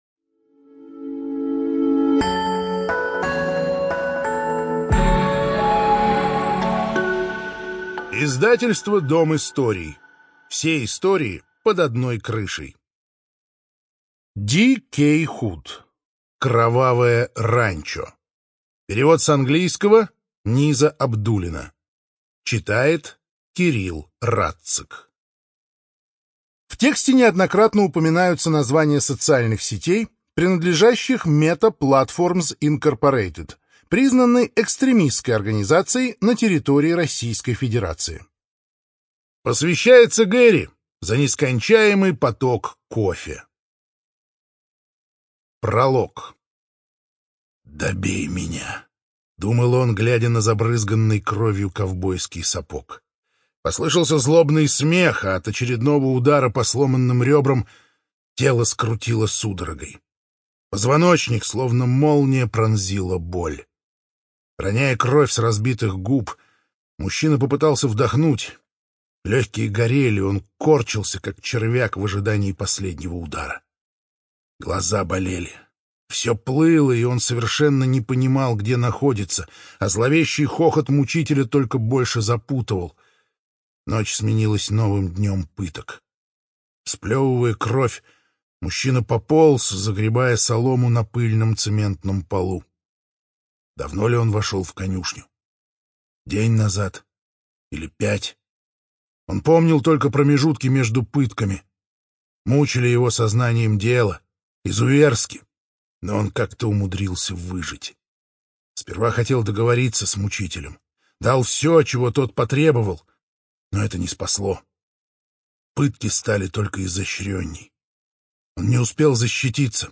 Студия звукозаписидом историй